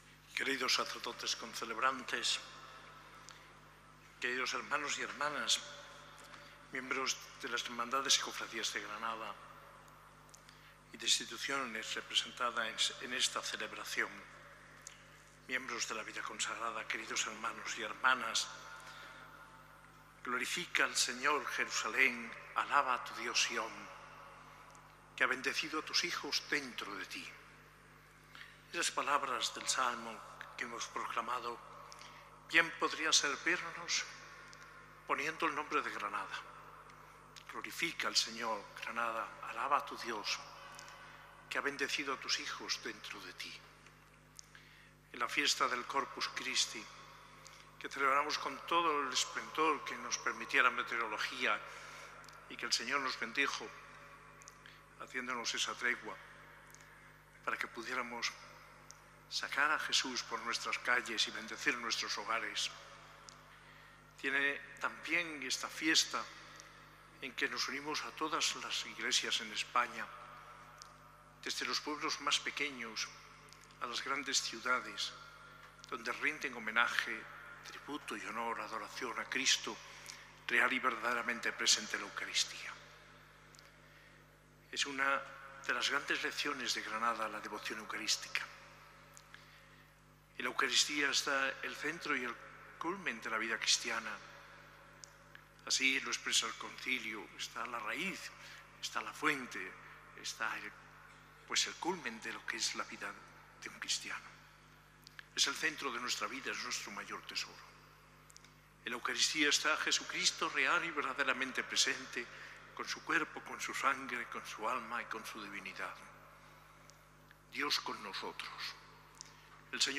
Homilía del arzobispo de Granada, Mons. José María Gil Tamayo, en la Eucaristía en la Solemnidad del Corpus Christi, el domingo 11 de junio de 2023.